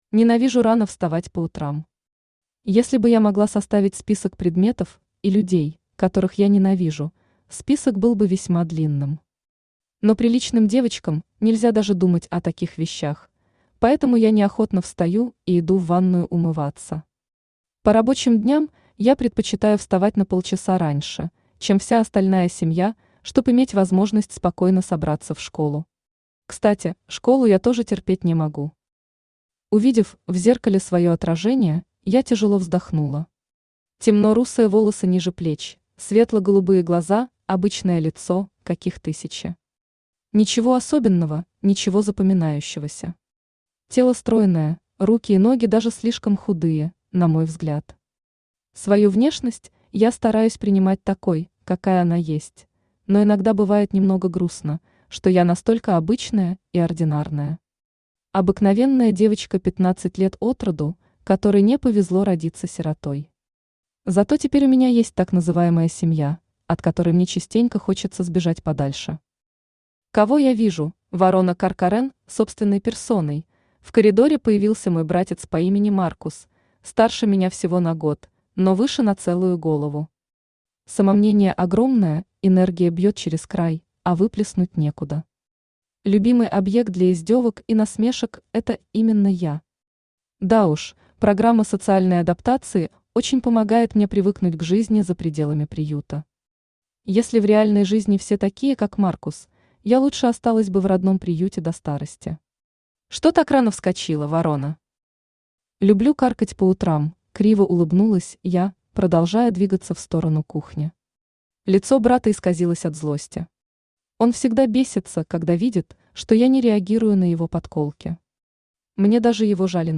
Аудиокнига Ледяная ведьма | Библиотека аудиокниг
Aудиокнига Ледяная ведьма Автор Лита Марсон Читает аудиокнигу Авточтец ЛитРес.